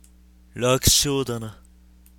RPG戦闘終了後キャラクター台詞です。
作った当時に出していた声を忘れたので、2通り録ってみました。
しっかし、マイクの集音力高すぎです。
マウスのクリック音まで入ってますよ。